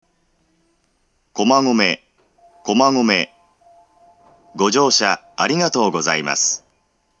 ※音声は１番線側の発車ベルスイッチ前の小ＶＯＳＳを除いて、全てユニペックス小型及びユニペックス小丸型から流れます。
１番線到着放送
加えて到着放送の鳴動が遅く、発車メロディーに被りやすいです。
komagome1bansen-totyaku2.mp3